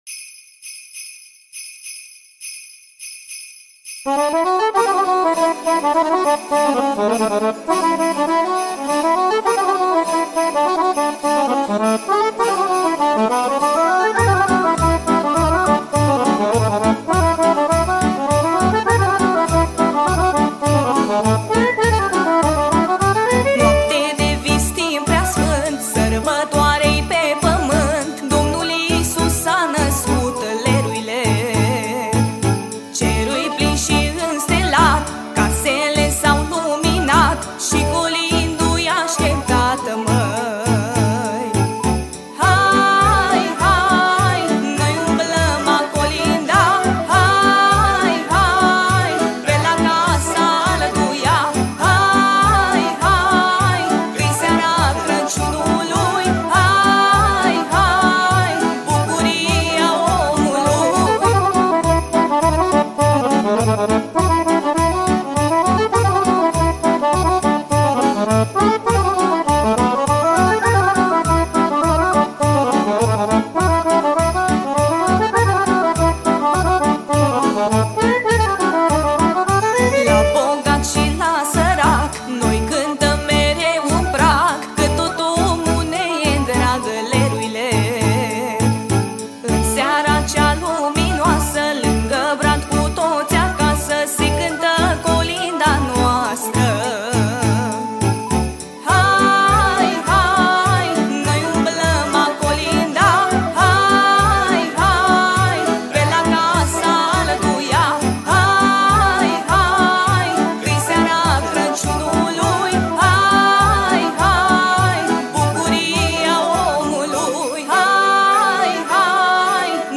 Data: 11.10.2024  Colinde Craciun Hits: 0